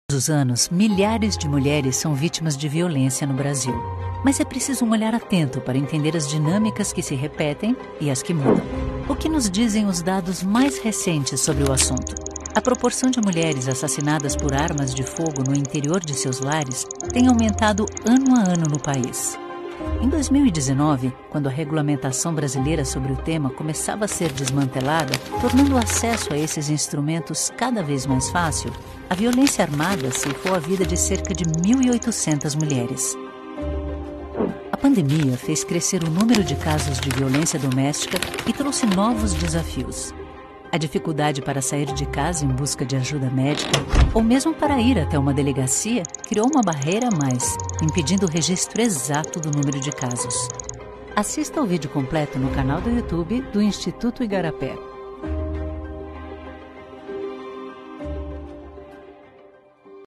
Narration
Je suis décrite comme une voix brésilienne chaleureuse et charmante.
Actuellement, mes fichiers audio sont enregistrés dans mon home studio à São Paulo, au Brésil, avec du matériel professionnel.
Mezzo-soprano